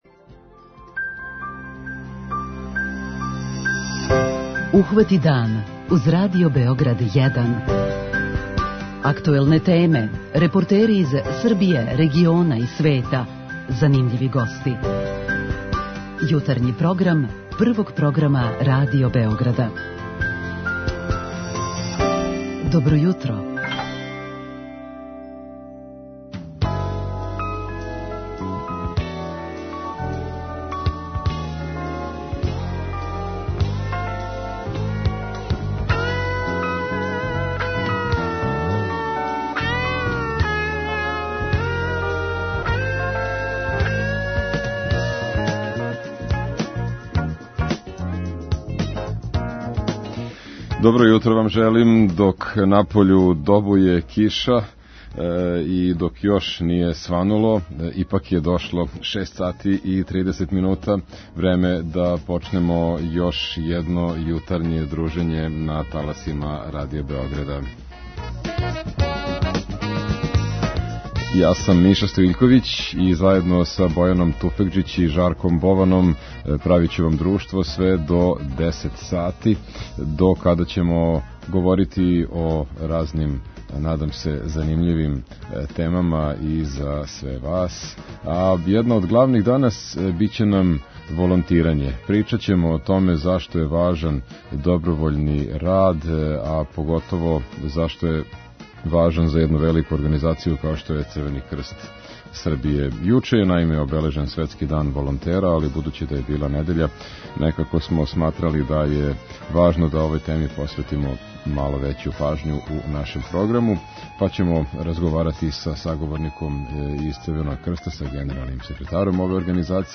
Више о волонтирању говориће нам гост из Црвеног крста, организација које умногоме зависи од добровољног рада.
Више о волонтирању говориће нам гост из Црвеног крста, организација које умногоме зависи од добровољног рада. преузми : 37.78 MB Ухвати дан Autor: Група аутора Јутарњи програм Радио Београда 1!